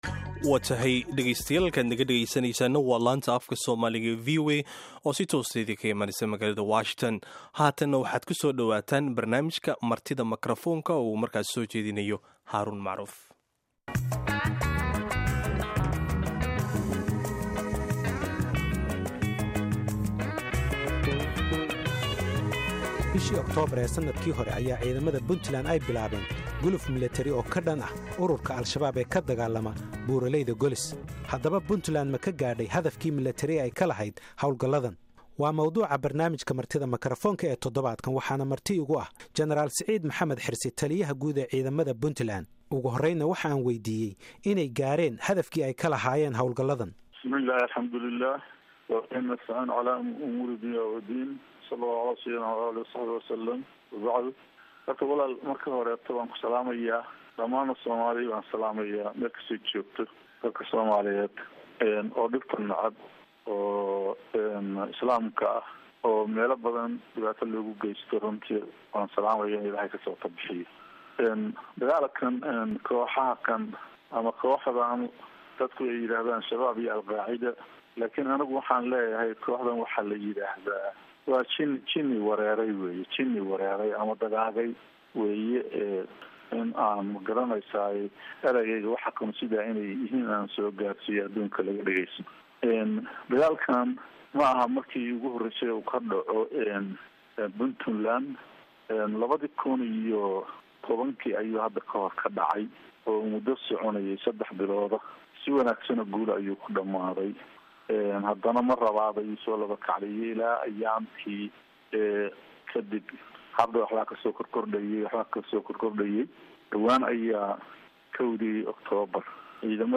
Dhagayso: Gen. Siciid Dheere Taliyaha Ciidamada Puntland oo VOA waraysi siiyey
Waxaana barnaamijka toddobaadkan Marti ku ah Generaal Siciid Maxamed Xirsi Taliyaha Ciidamada Puntland.